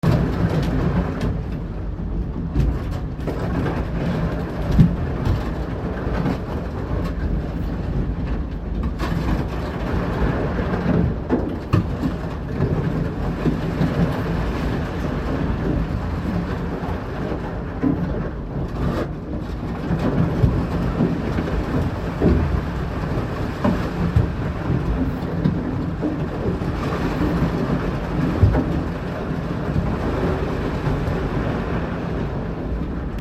Whether we saw anything else, it was an amazing experience to be among the pack ice, both visually and aurally – the sound inside our cabin, which is at the watericeline, was astounding.
Ice-on-the-hull-2.mp3